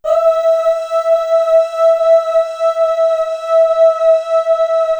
Index of /90_sSampleCDs/Best Service ProSamples vol.55 - Retro Sampler [AKAI] 1CD/Partition C/CHOIR UHH